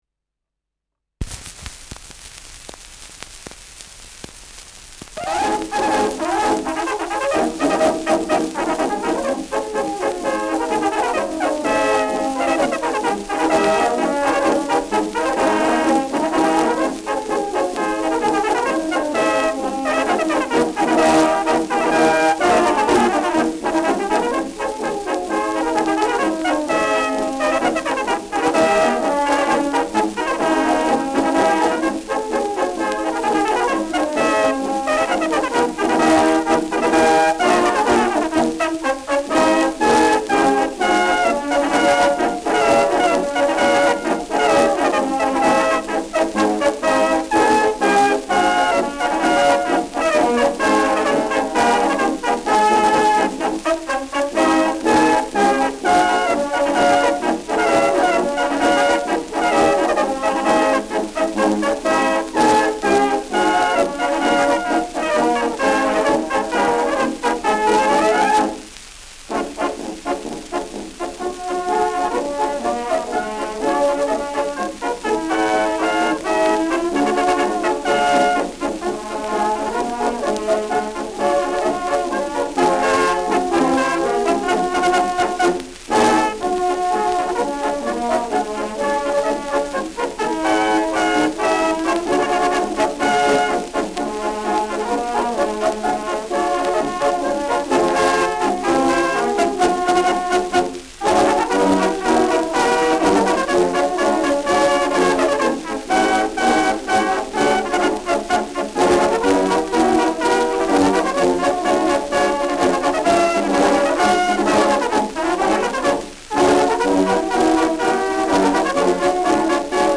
Schellackplatte
Tonrille: leichte Kratzer durchgängig
leichtes Rauschen
Militärmusik des k.b. 2. Ulanen-Regiments, Ansbach (Interpretation)
[Ansbach] (Aufnahmeort)